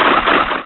Cri de Vibraninf dans Pokémon Rubis et Saphir.